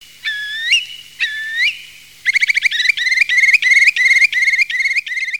Courlis cendrés
Numenius arquata
courlis.mp3